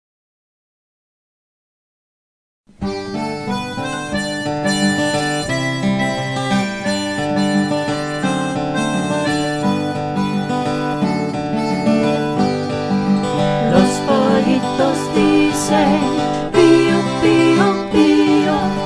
Traditional Spanish Song Lyrics and Sound Clip